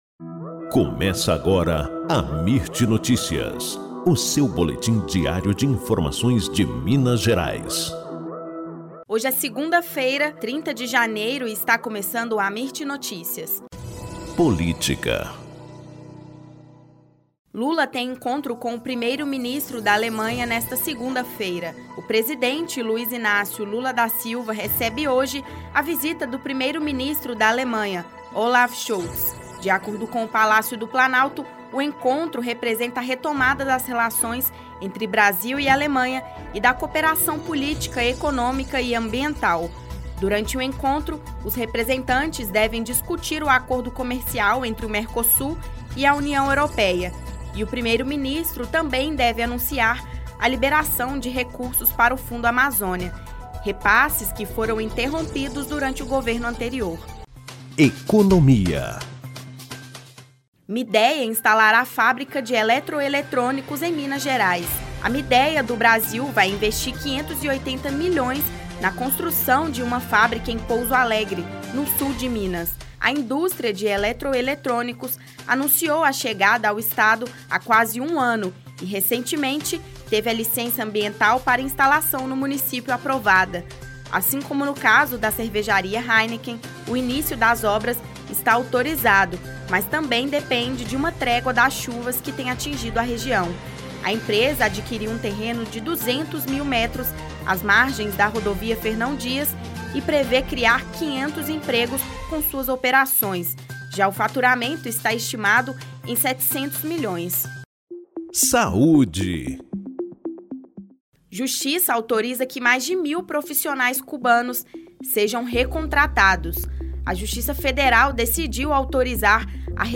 Boletim Amirt Notícias – 30 de janeiro